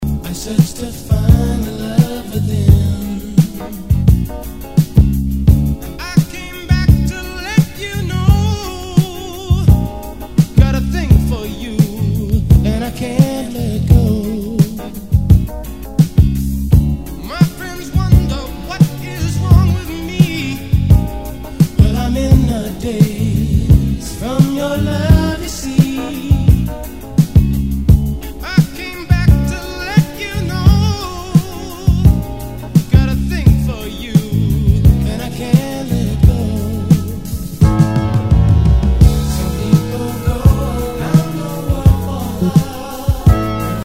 Tag       OTHER ROCK/POPS/AOR